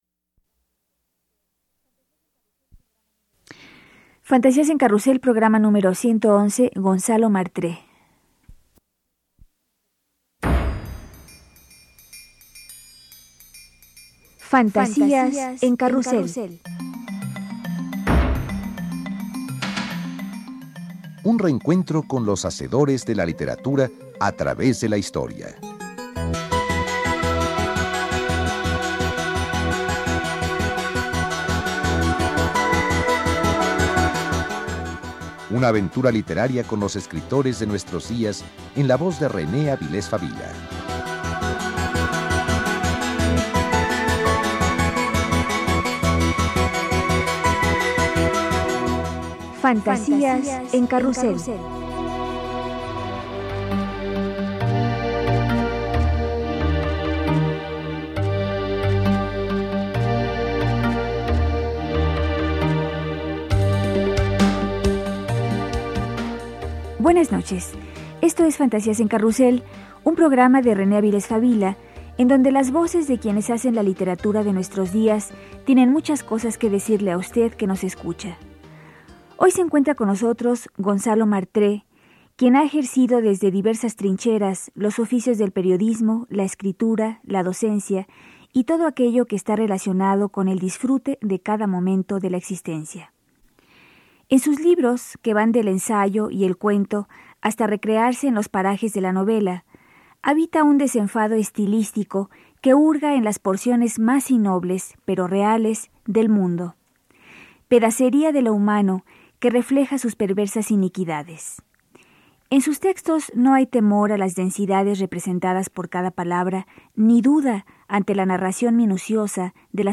entrevistado sobre su obra